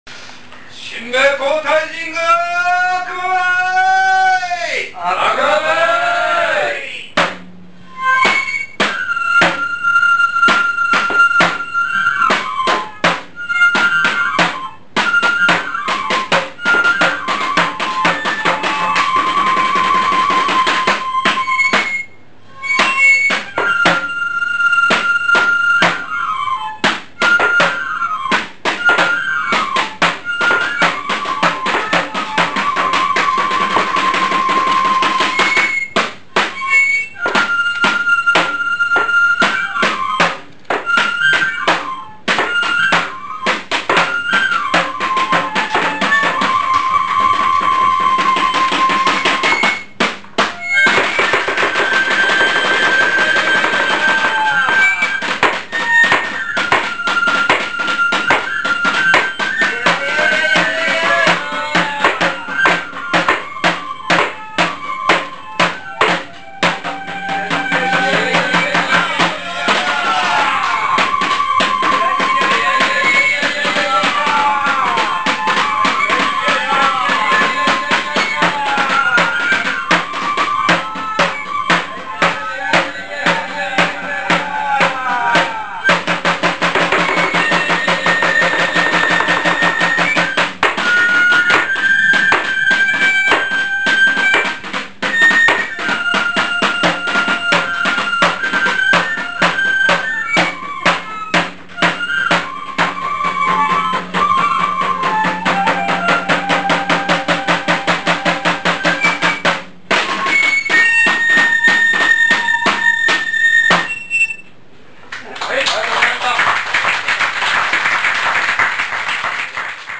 モノトーンからの世界へ、、、　　　　生録の曲名を選択し
山谷神楽 (新津市 山谷 諏訪神社)
新津市山谷 諏訪神社の春祭りで「山谷神楽」が奉納されます